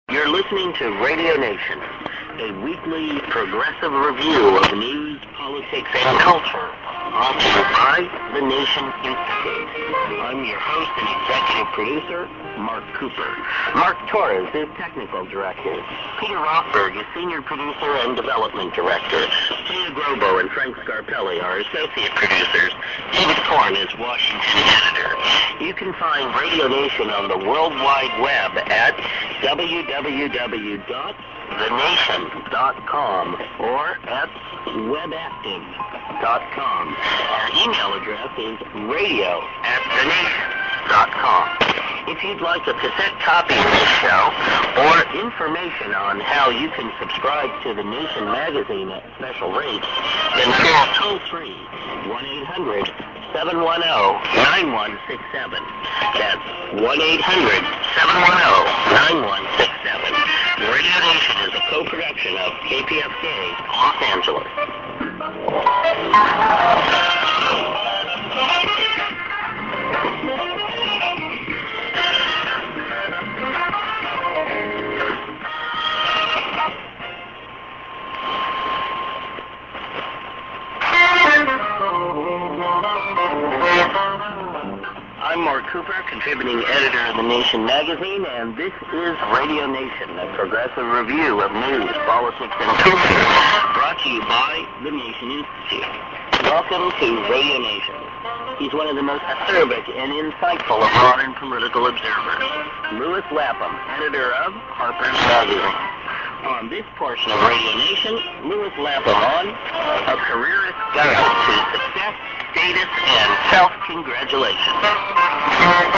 ->Call+Web Addr->ANN(man:Radio the?? Nation)->　＊別の局の中継か